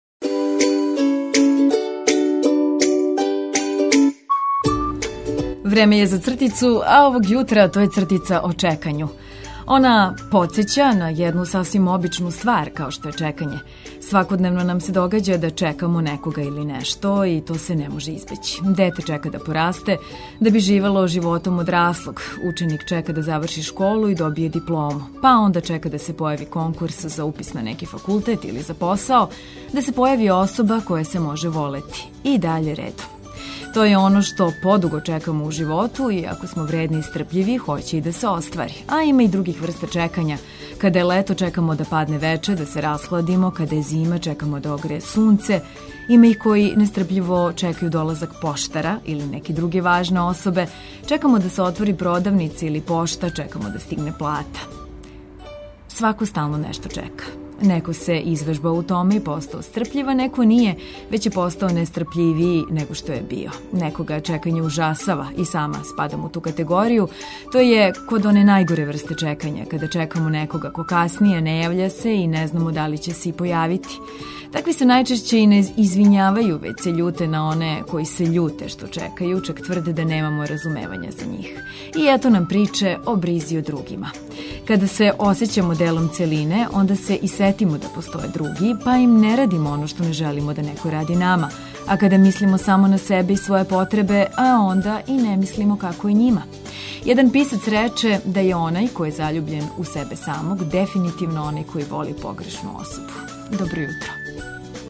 Сјајно расположење, корисне информације и величанствени хитови су ту.